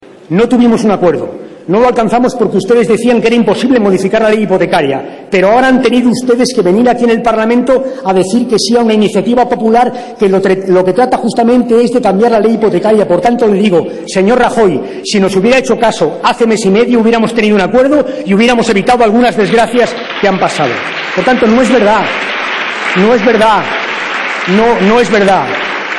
Alfredo P. Rubalcaba. Debate del Estado de la Nación 20/02/2013